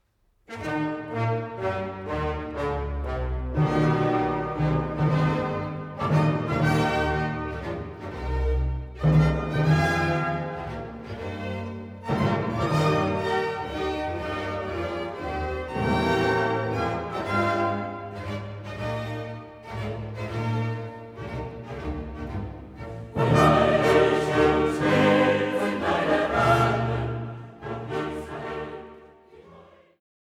Chor der Israeliten